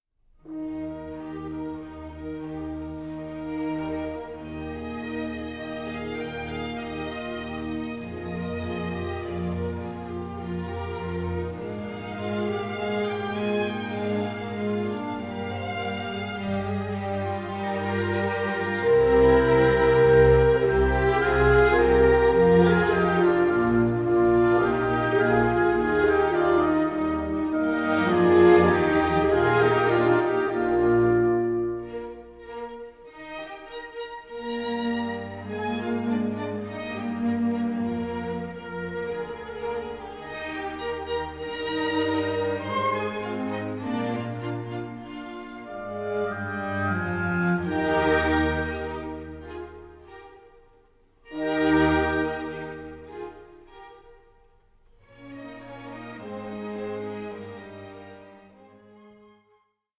Concerto for Violin & Orchestra No. 1 in B-Flat Major
Adagio 8:47